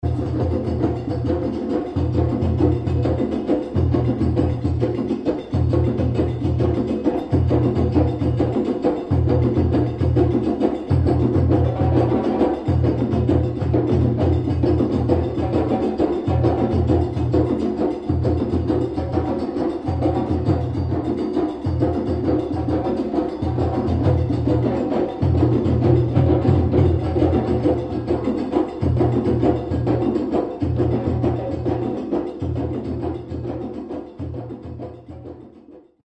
描述：soli节奏记录非洲鼓不简单...
Tag: H2 缩放 顿顿 西 非洲索利 非洲鼓